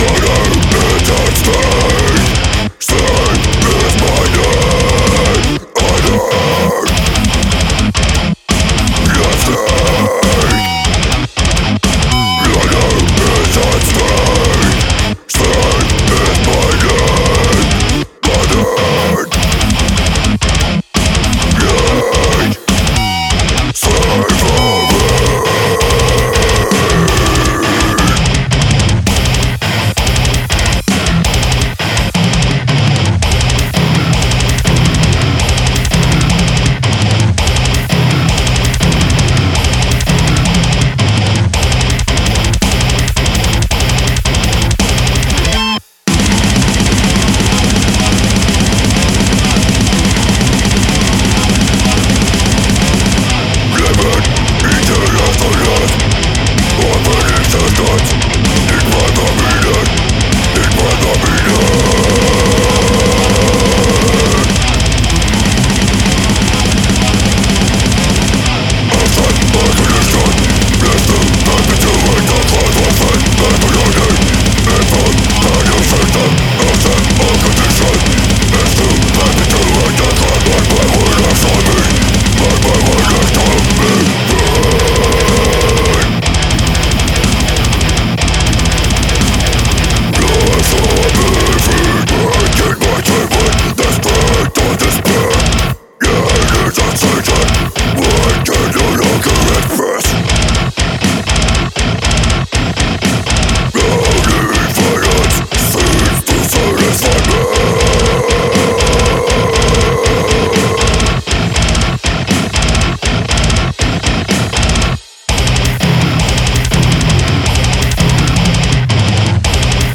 Tags: Metal Drum n bass hip hop